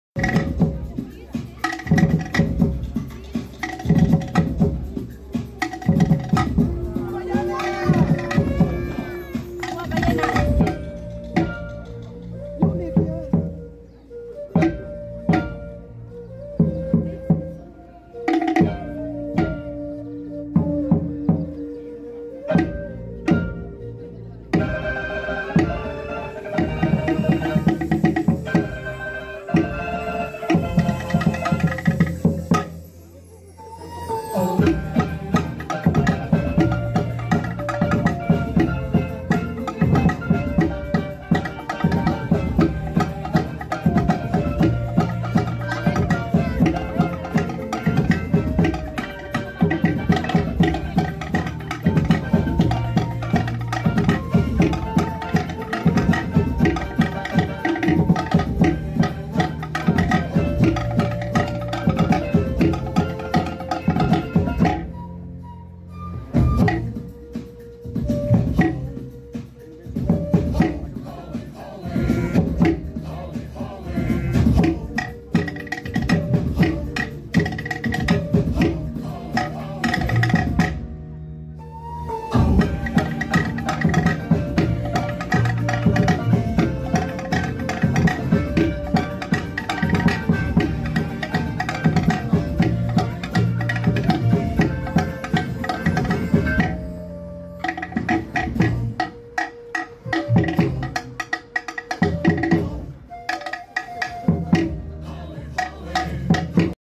live music and different instruments